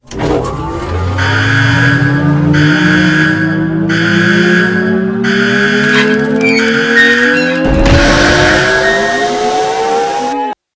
crank.ogg